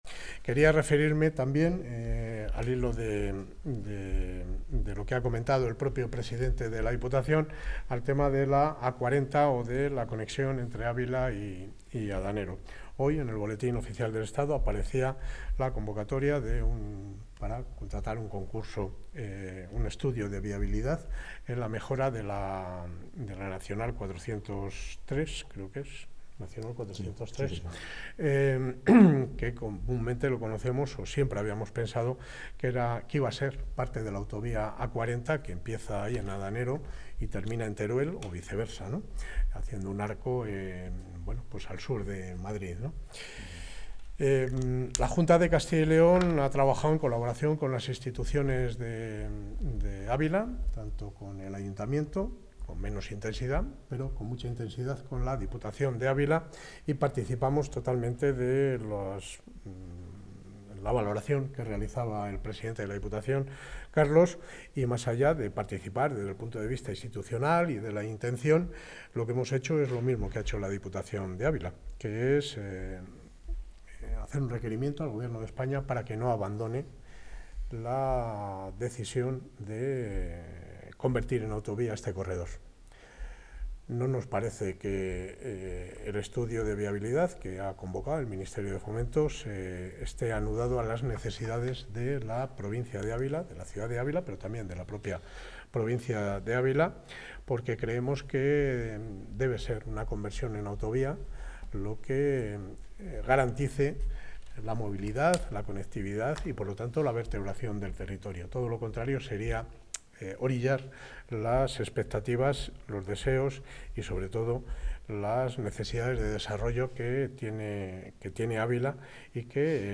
Declaraciones del consejero.
El consejero de Movilidad y Transformación Digital ha anunciado hoy, durante un acto en Ávila, la presentación de un requerimiento previo al Ministerio de Transportes y Movilidad Sostenible para que reconsidere la decisión de conectar por autovía Ávila con la A-6 hacia Madrid.